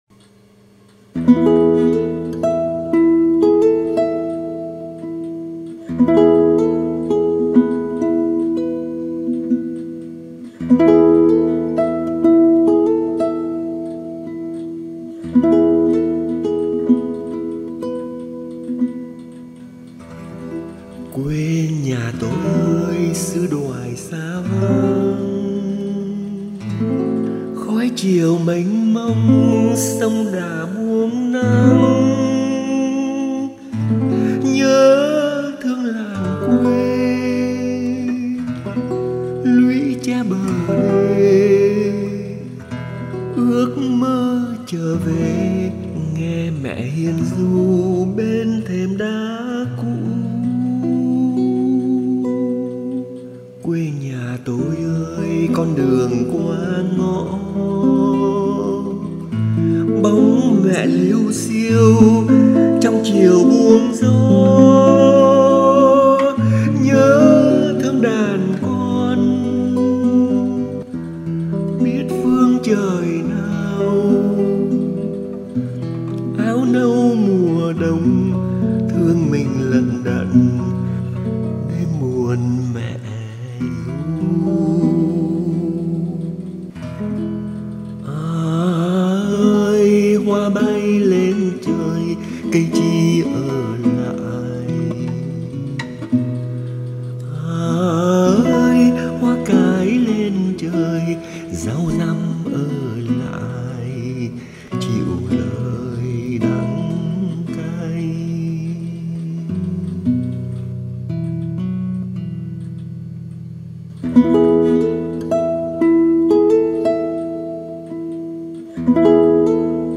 đàn và hát
Phần đàn mở đầu bằng đàn ghi-ta được mượn từ phiên bản này.